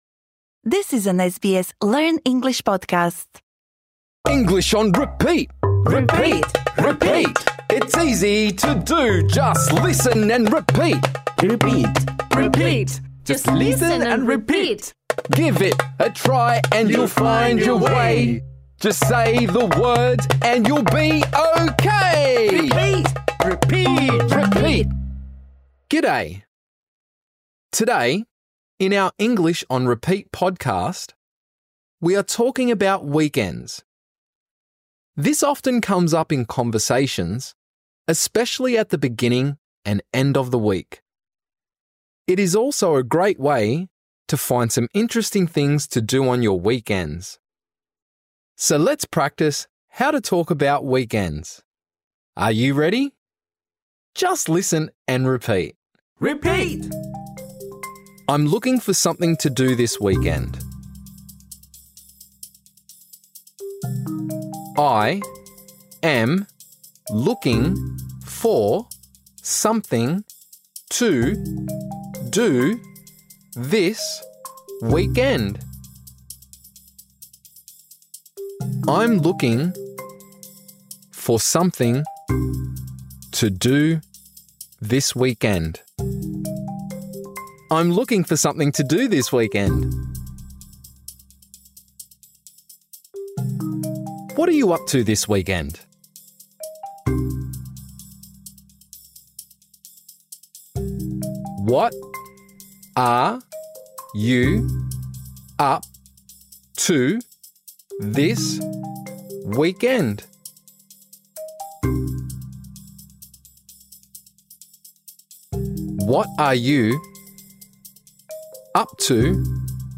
This lesson is designed for easy-level learners. In this episode, we practise saying the following phrases: I’m looking for something to do this weekend.